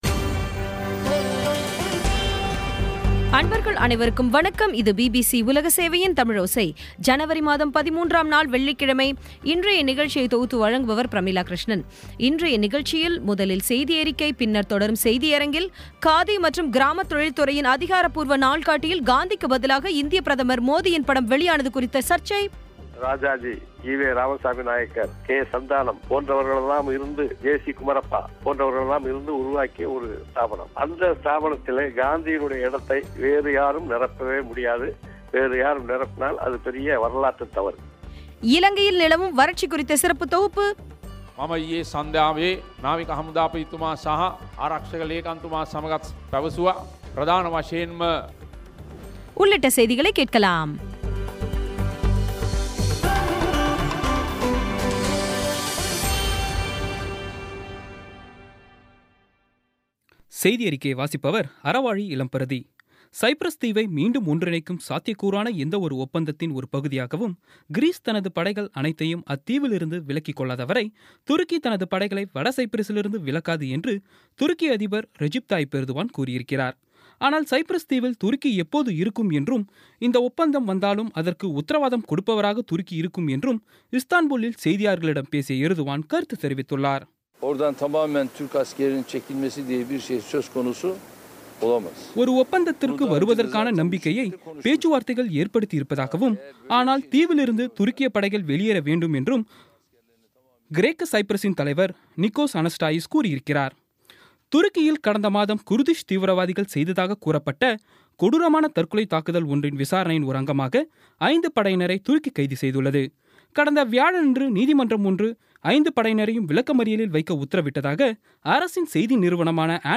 இன்றைய நிகழ்ச்சியில் முதலில் செய்தியறிக்கை, பின்னர் தொடரும் செய்தியரங்கில் காதி மற்றும் கிராம தொழில் துறையின் அதிகாரப்பூர்வ நாள்காட்டியில் காந்திக்கு பதிலாக இந்திய பிரதமர் மோதியின் படம் வெளியான சர்ச்சை குறித்த பேட்டி இலங்கையில் வறட்சி நிவாரண பணிகளில் முப்படையினரையும் ஈடுபடுத்தப் போவதாக ஜனாதிபதி மைத்திரிபால சிறிசேன அறிவிப்பு ஆகியவை கேட்கலாம்